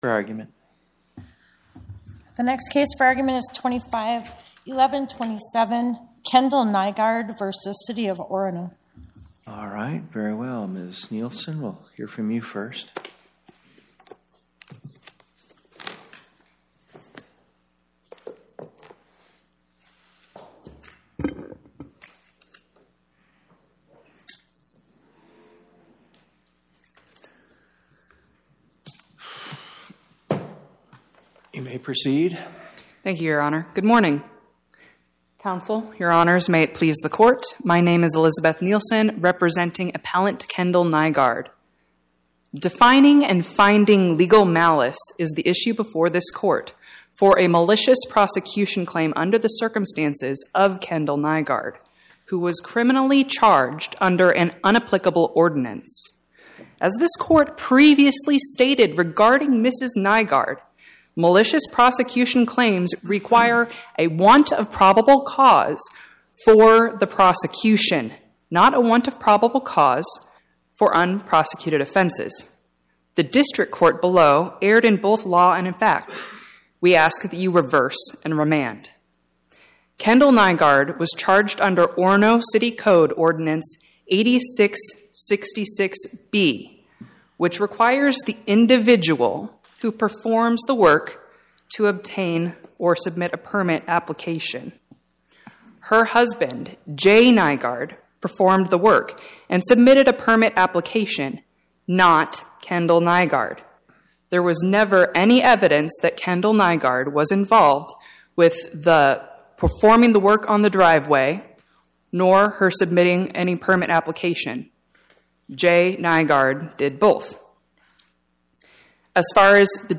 Oral argument argued before the Eighth Circuit U.S. Court of Appeals on or about 10/22/2025